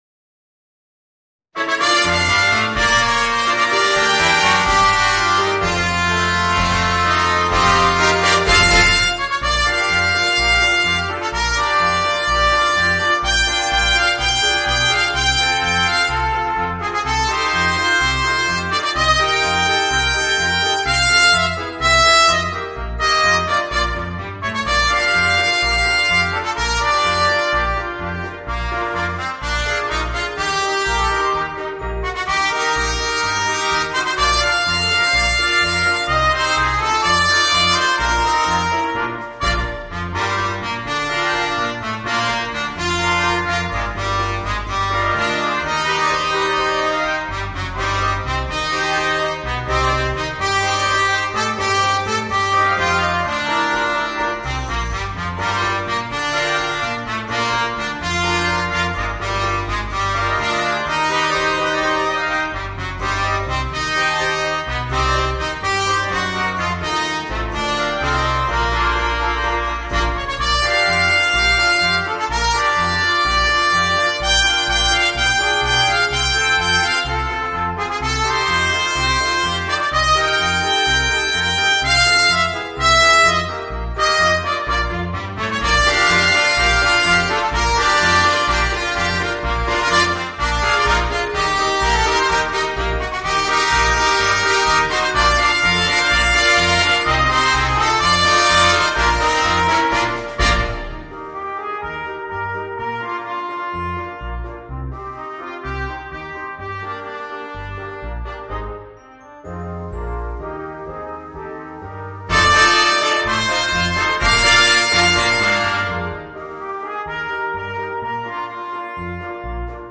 Voicing: 4 Trumpets and Piano